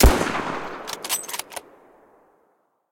sounds_rifle_fire_cock.ogg